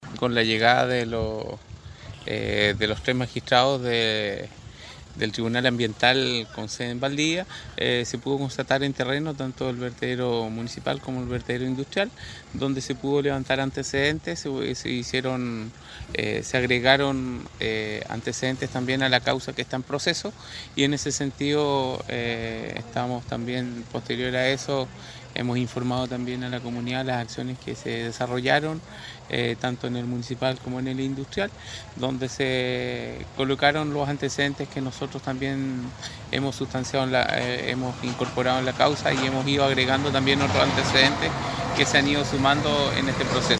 El alcalde de Chonchi, Fernando Oyarzún, sostuvo que se cumplió con la inspección de los tres ministros, esperando que el tribunal pueda sancionar debidamente a la empresa propietaria.